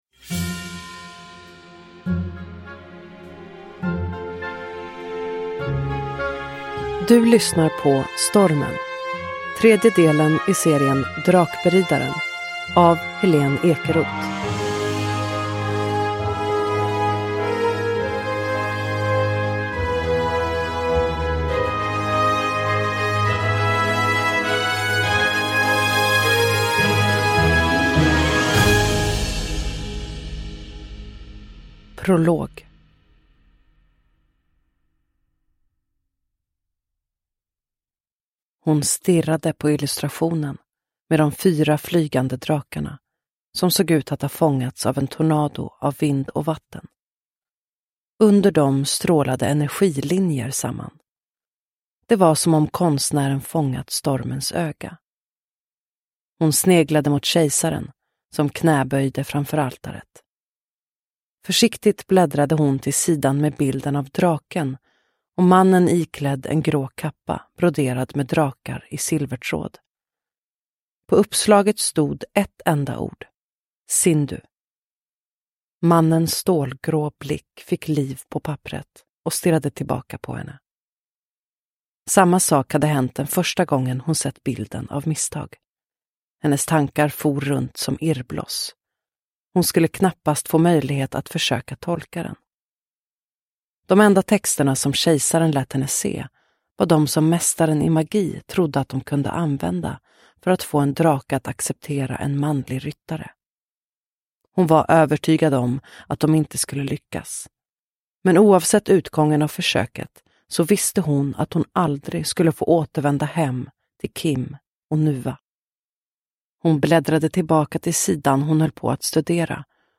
Stormen – Ljudbok – Laddas ner